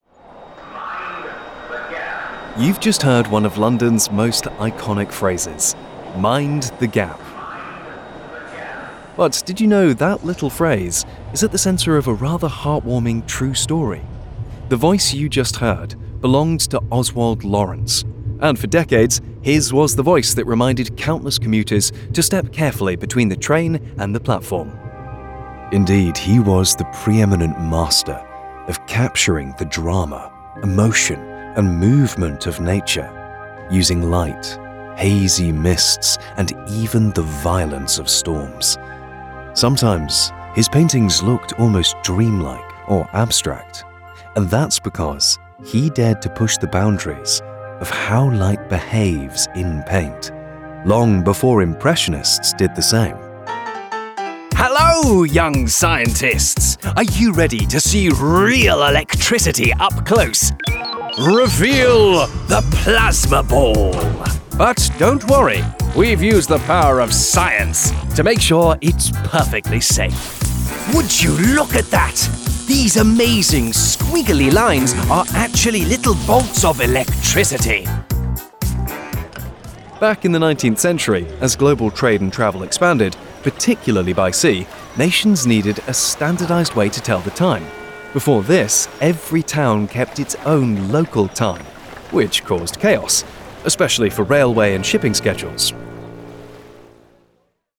Comercial, Natural, Amable, Cálida, Versátil
Audioguía